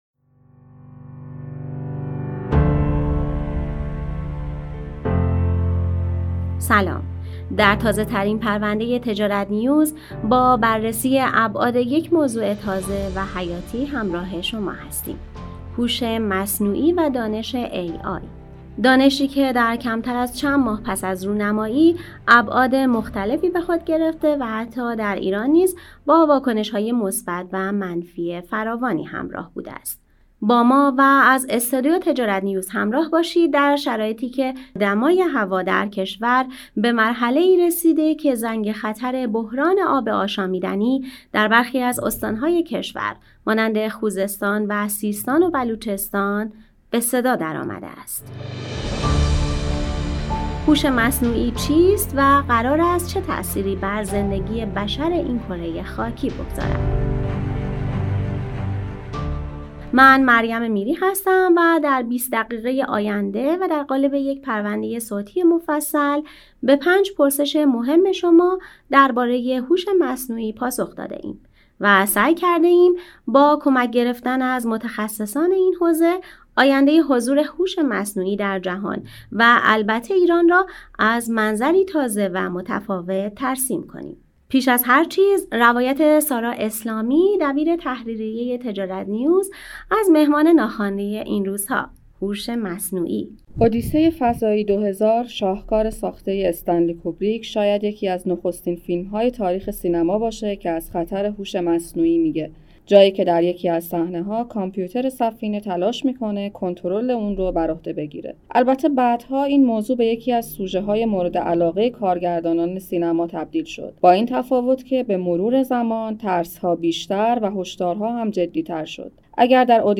با ما و از استودیو تجارت‌نیوز همراه باشید در شرایطی که دمای هوا در کشور به مرحله‌ای رسیده که زنگِ خطر بحرانِ آب آشامیدنی در برخی از استان‌های کشور مانند خوزستان و سیستان و بلوچستان به صدا درآمده است.